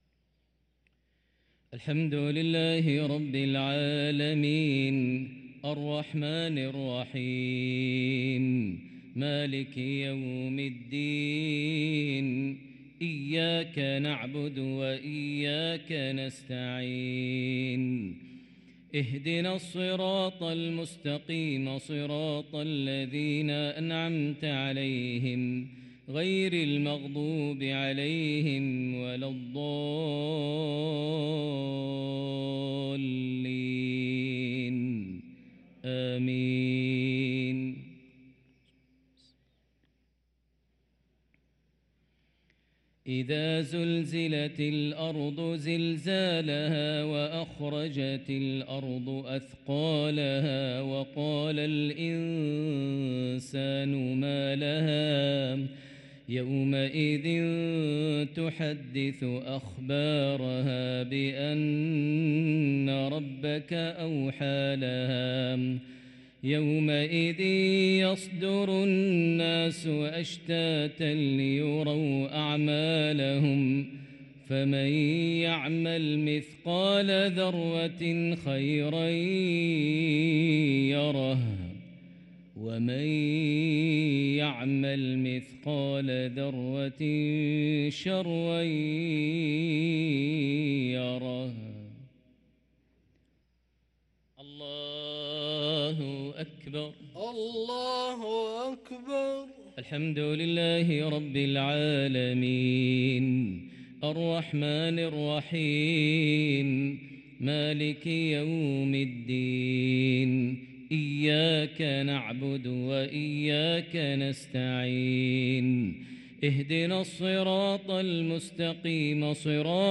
صلاة المغرب للقارئ ماهر المعيقلي 3 ربيع الآخر 1445 هـ
تِلَاوَات الْحَرَمَيْن .